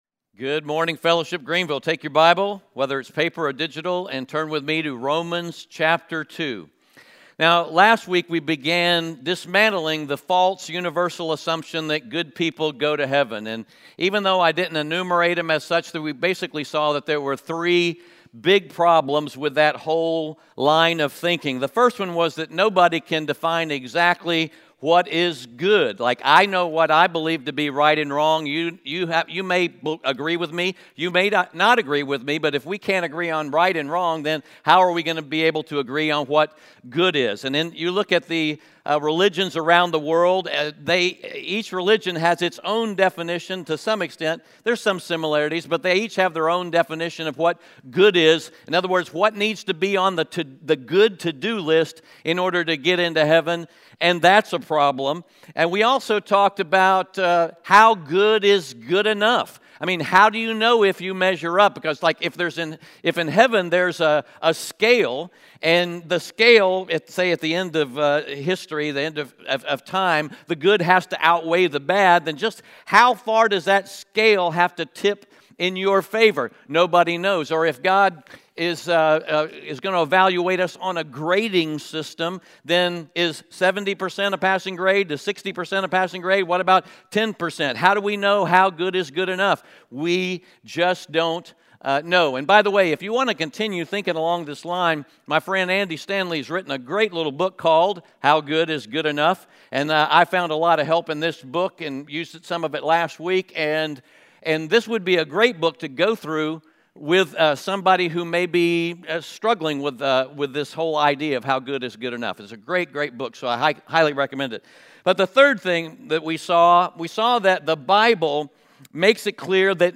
Romans 2:17-29 Audio Sermon Notes (PDF) Onscreen Notes Ask a Question *We are a church located in Greenville, South Carolina.